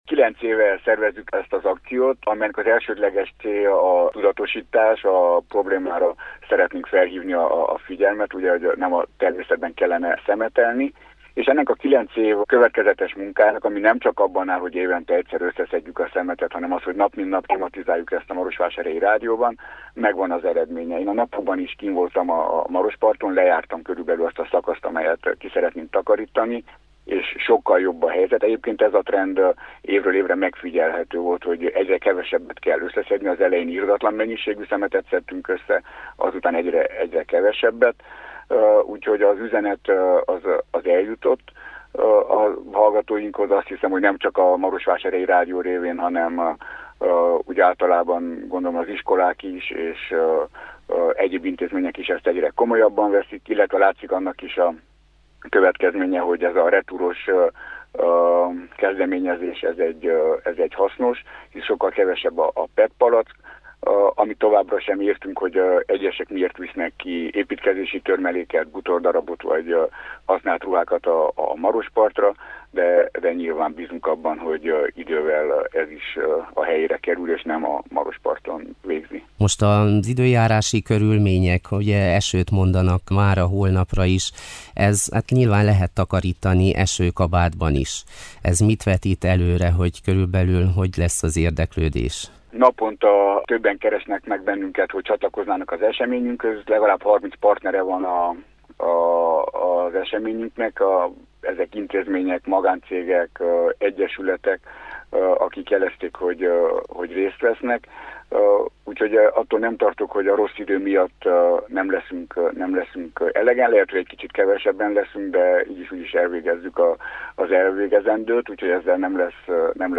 Beszélgetőtárs